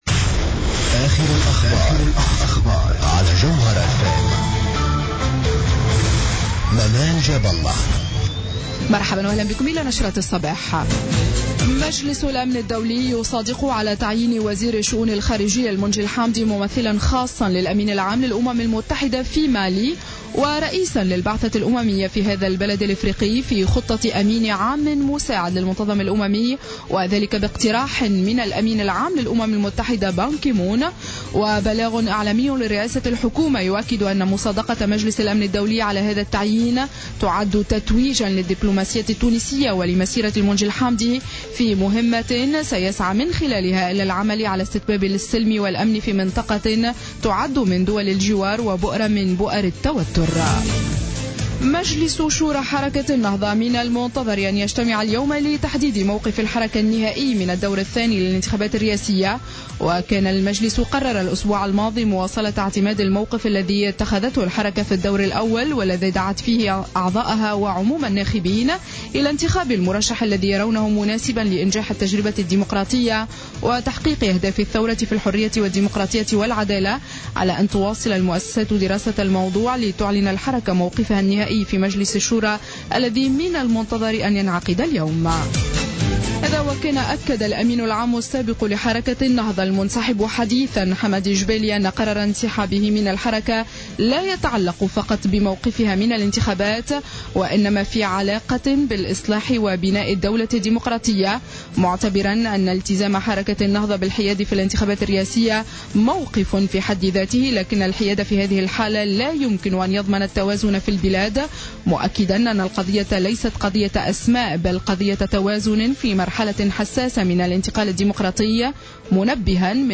نشرة أخبار السابعة صباحا ليوم 13-12-14